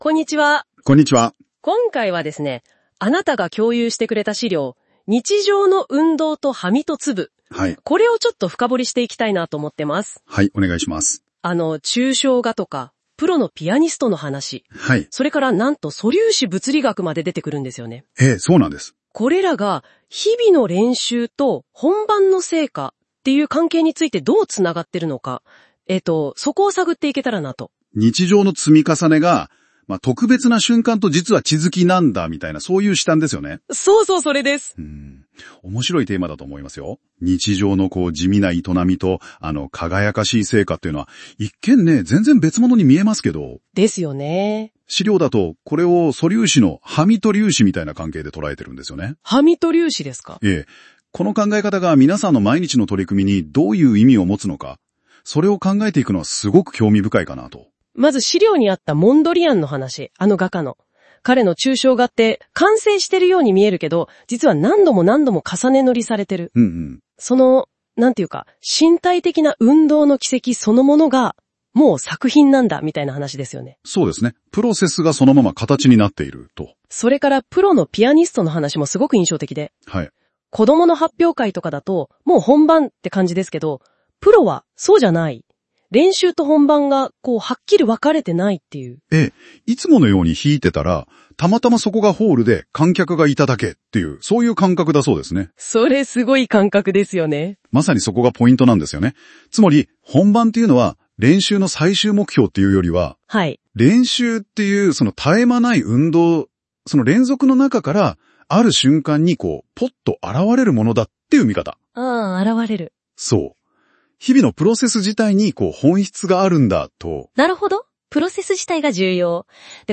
これは本題じゃないんですが、今回の記事の内容を、notebookLM という超ウルトラおもしろツールでポッドキャスト風に要約してもらいました。